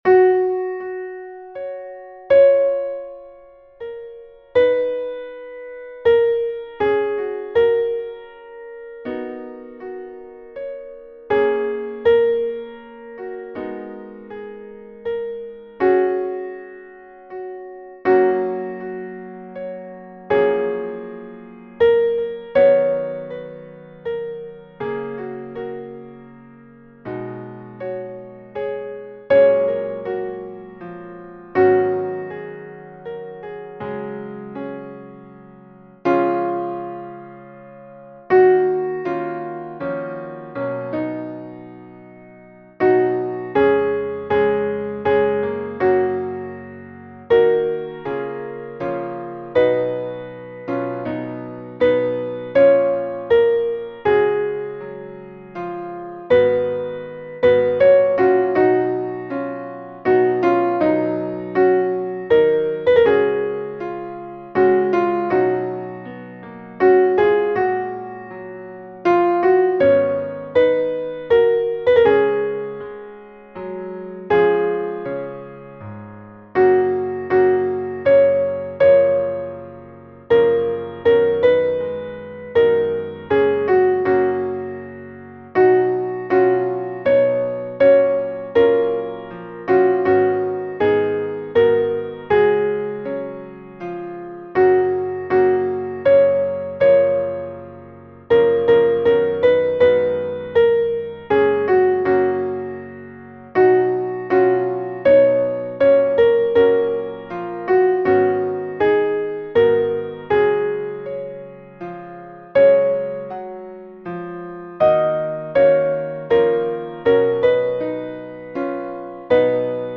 Versions piano